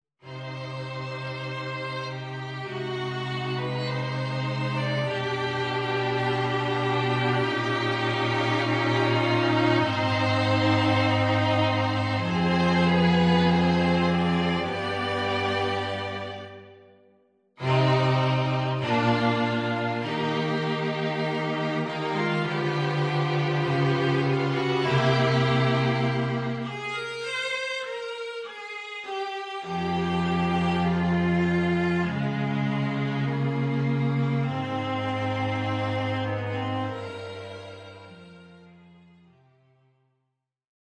II  Very solemn and very slowly
Excerpt from Second Movement (String Quartet)